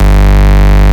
VES2 Bass Shots
VES2 Bass Shot 026 - G.wav